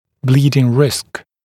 [‘bliːdɪŋ rɪsk][бли:дин риск]риск развития кровотечения